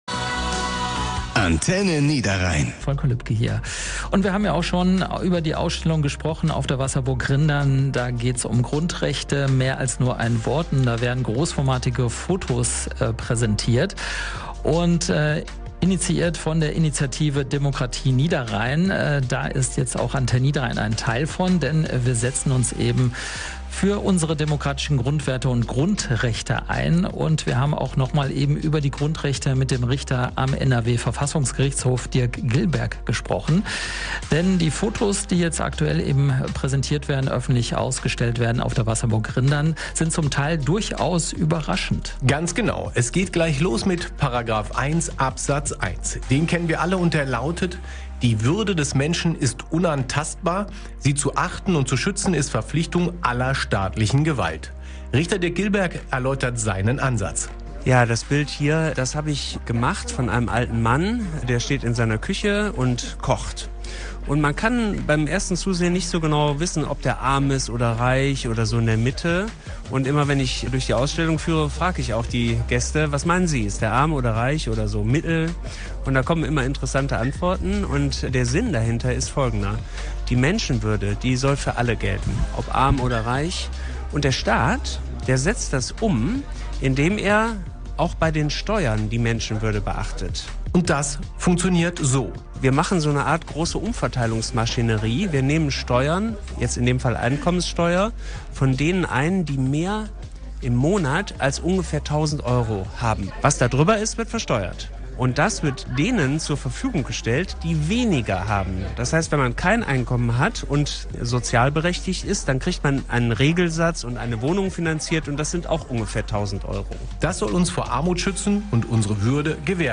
Zusammen mit dem NRW-Verfassungsrichter Dirk Gilberg werden wir in den kommenden Tagen immer mal wieder eines unserer Grundrechte vorstellen, hier bei AN.